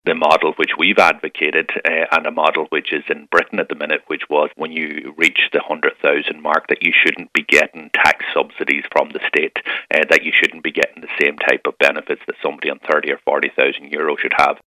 Sinn Féin’s Finance Spokesperson and Donegal Deputy Pearse Doherty is in favour of the move but says the higher rate should only apply to those on over 100,000: